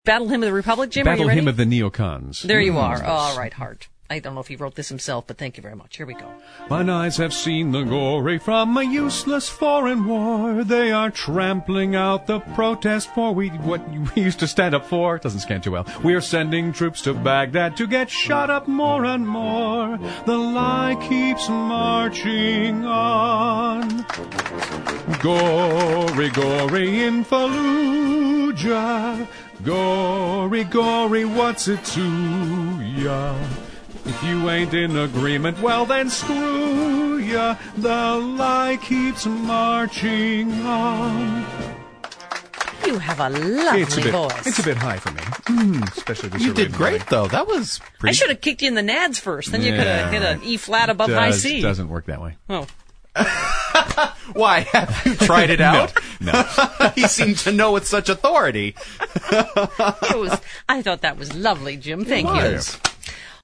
The Version heard on the Nationally Syndicated Stephanie Miller Show: